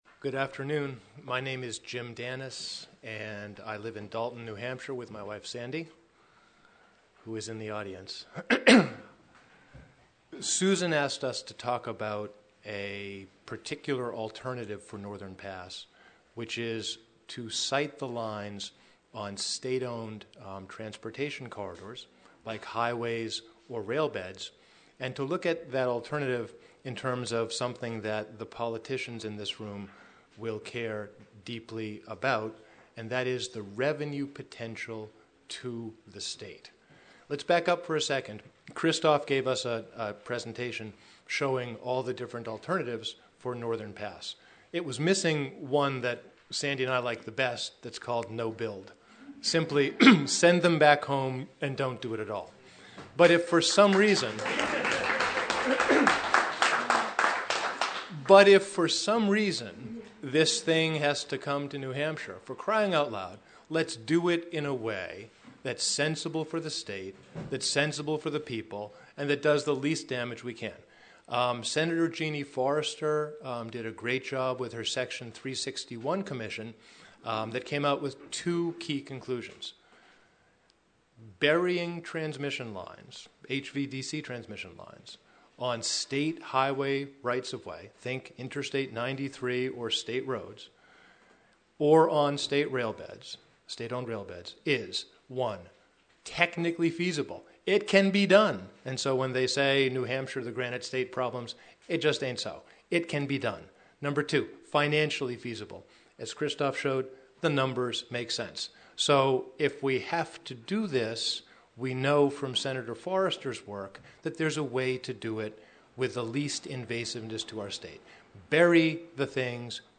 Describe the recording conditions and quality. About 135 people attended a meeting of concerned citizens at the Easton Town Hall for a discussion on a broad range of topics relating to Northern Pass about the Connecticut Headwaters easement, alternatives to Northern Pass, costs of burying the line and potential revenue to the state.